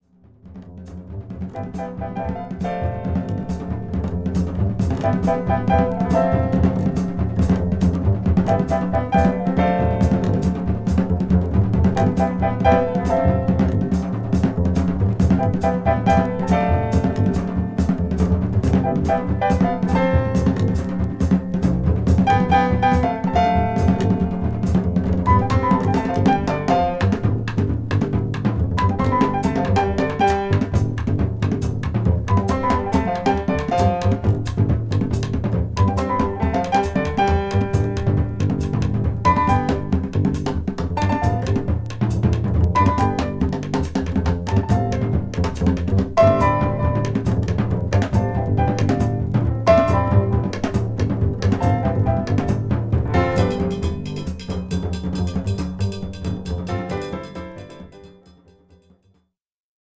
ホーム ｜ JAZZ